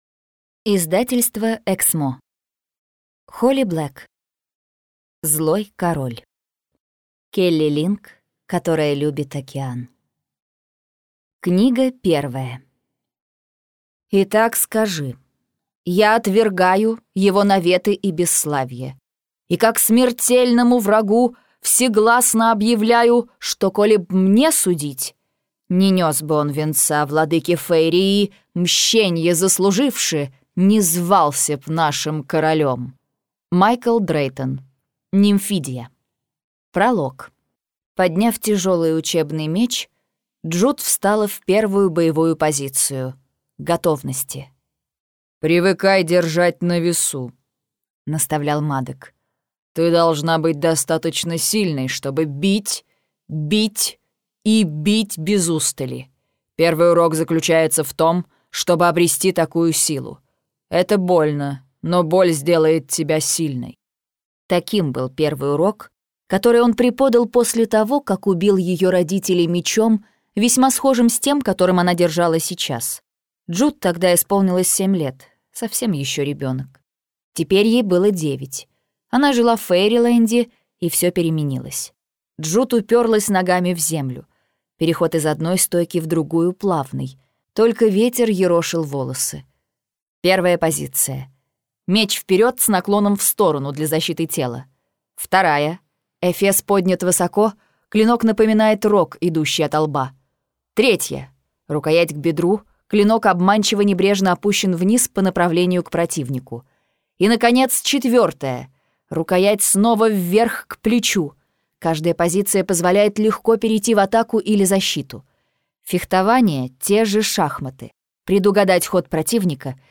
Аудиокнига Злой король - купить, скачать и слушать онлайн | КнигоПоиск